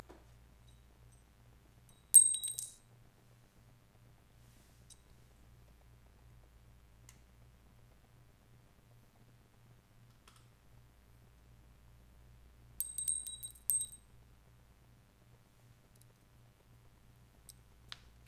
Category 🗣 Voices
bullet bullet-casings casing ding ejected empty foley shell sound effect free sound royalty free Voices